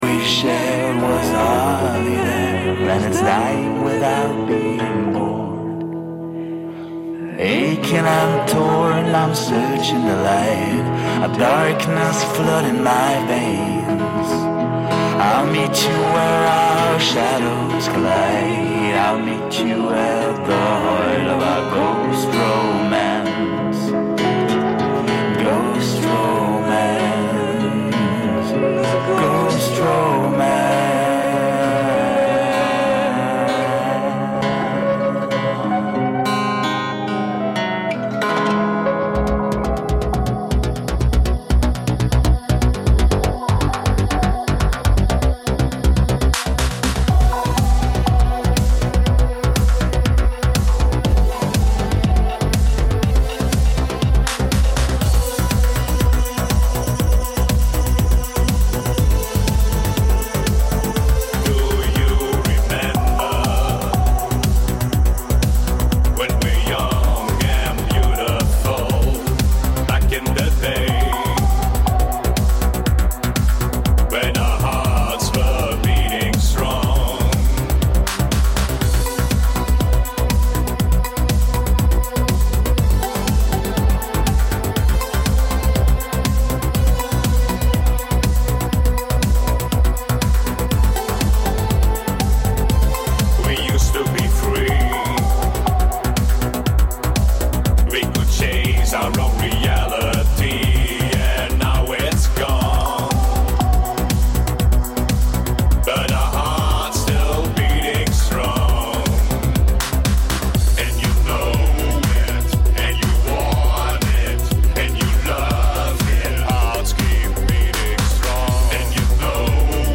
Musiksendung